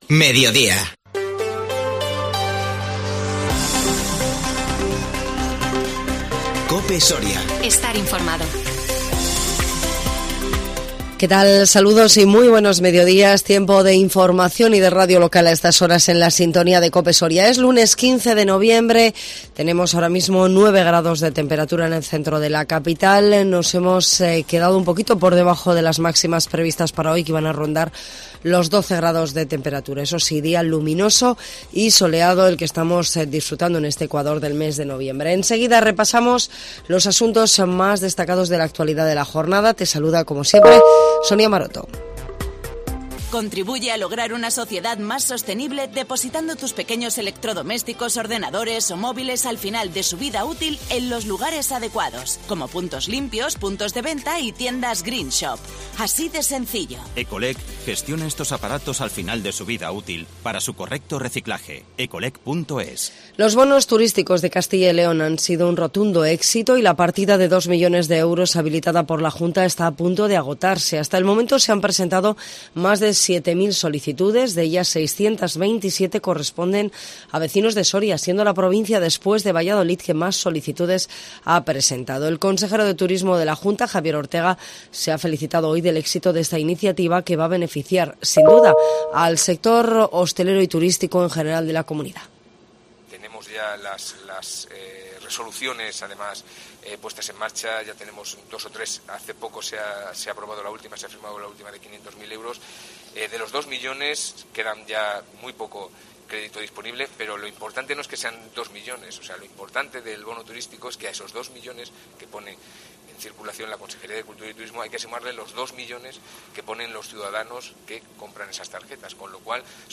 INFORMATIVO MEDIODÍA 15 NOVIEMBRE 2021